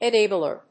/ɛˈnebʌlɝ(米国英語), eˈneɪbʌlɜ:(英国英語)/